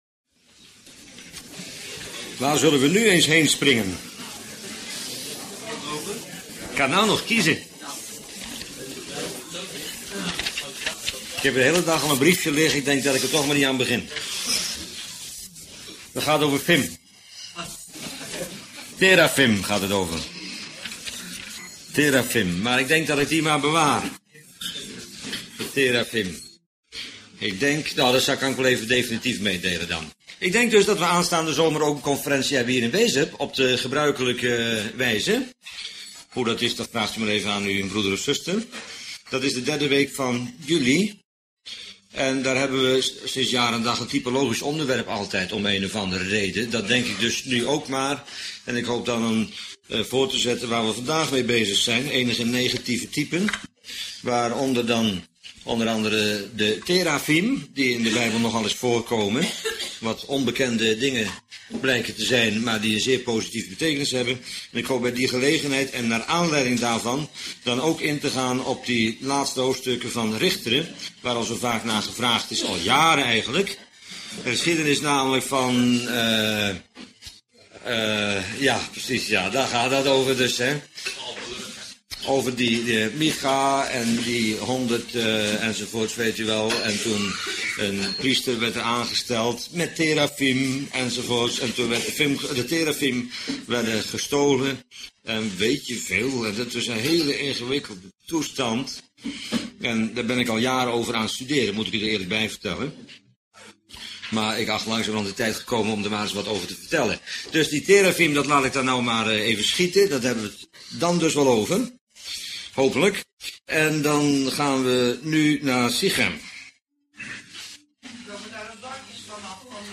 Bijbelstudie lezingen